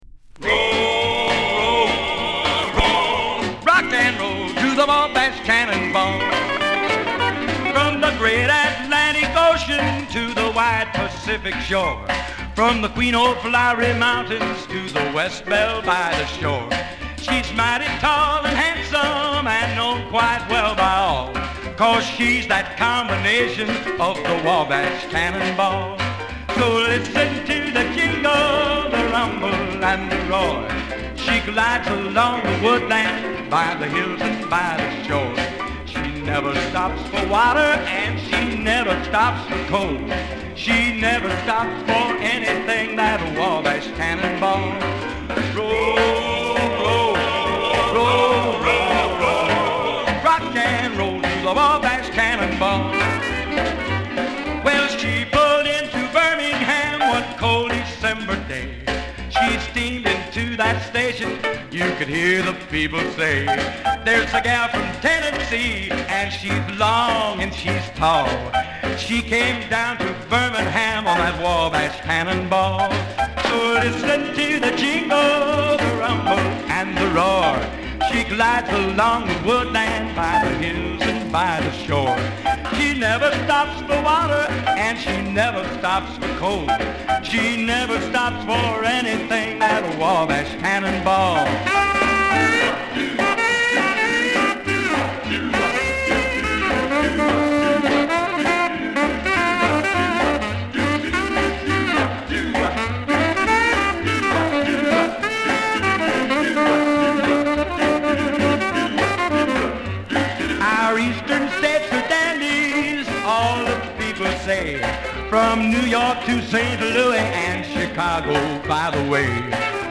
ポピュラー・ミュージック黎明期から活動するカントリー〜ポップ〜ロックンロール・シンガー。
足早に突き進む様が気持ちい。